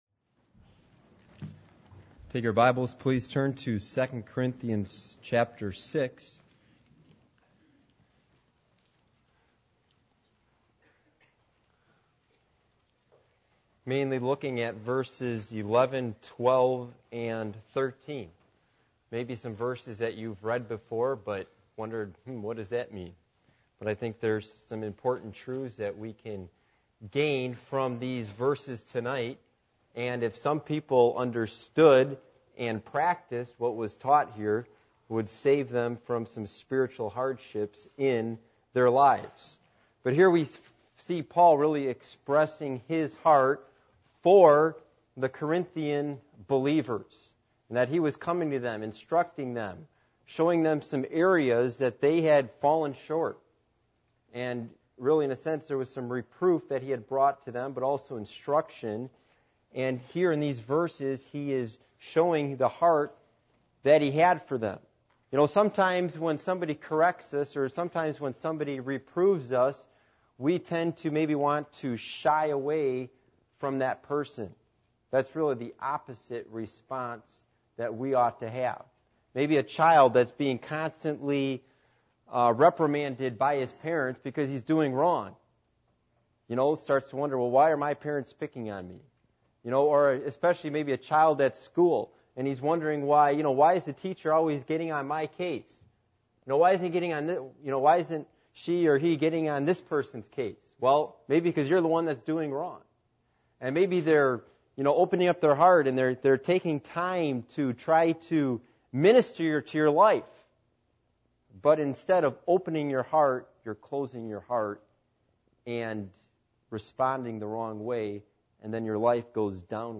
2 Corinthians 6:11-13 Service Type: Sunday Evening %todo_render% « What Is God’s Purpose For Your Life?